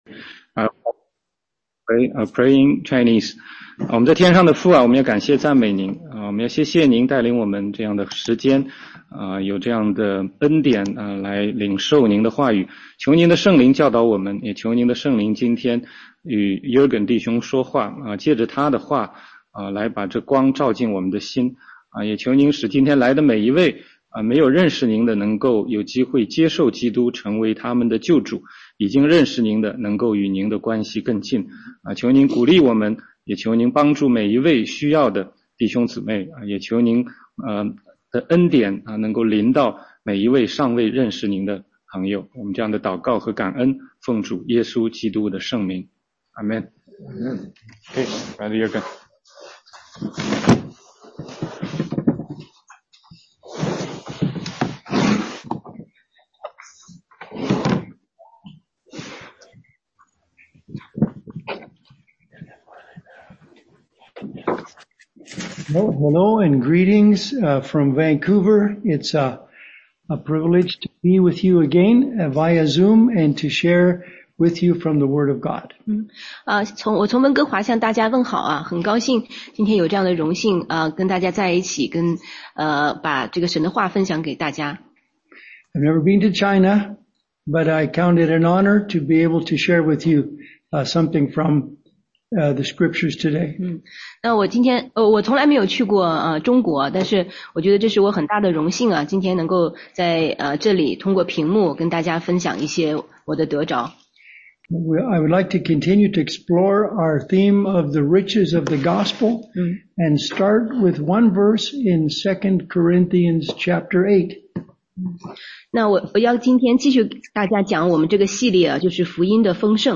中英文查经